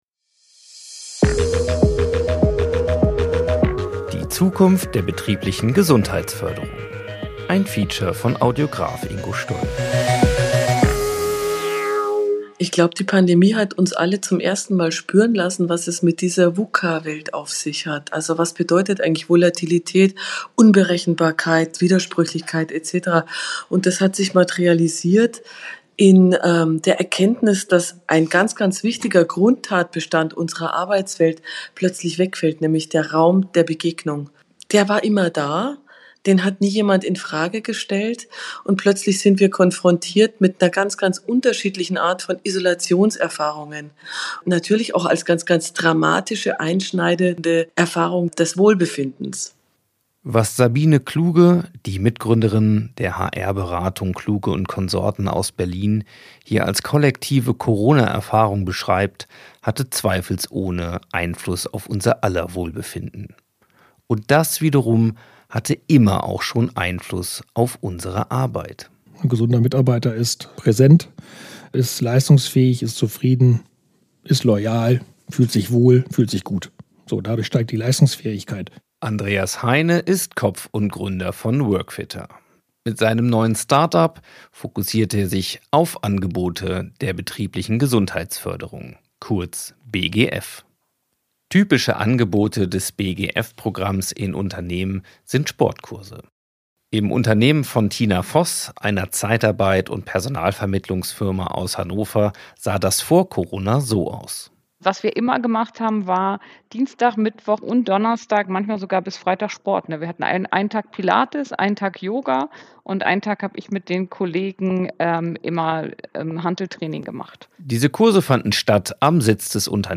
Hinweis: Das Feature ist erstmal als Podcast am 8.09.2022 auf der WORK FITTER Website veröffentlich worden. Wie sieht die Zukunft der betrieblichen Gesundheitsförderung aus?